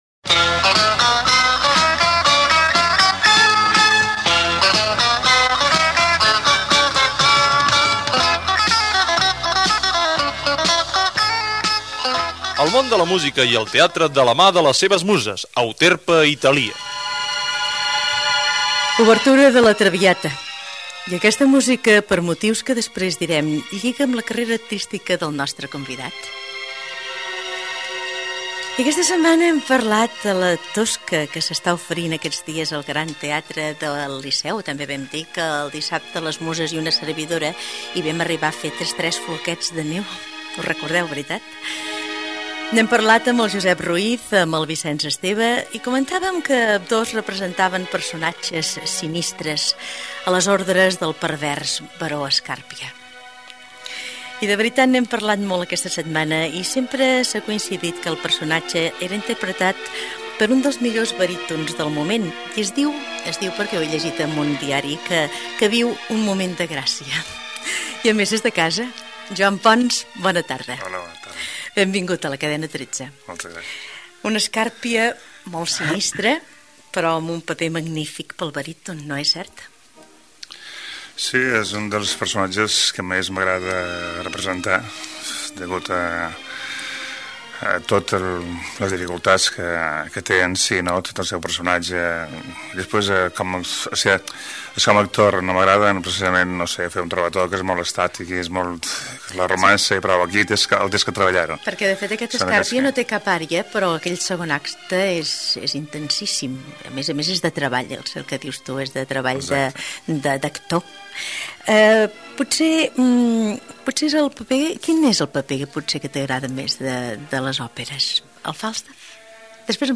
Careta del programa. Entrevista al baríton Joan Pons que actua al Gran Teatre del Liceu